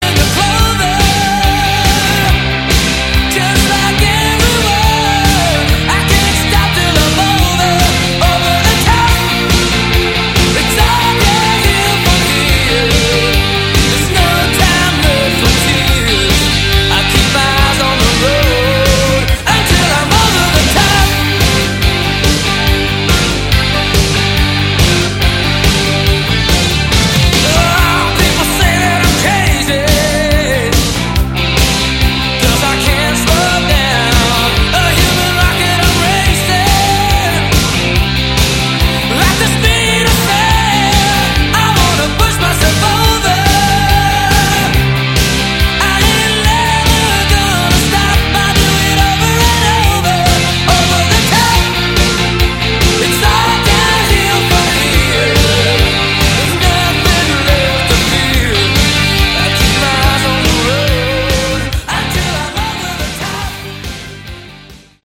Category: AOR
piano, keyboards
electric guitar, mandolin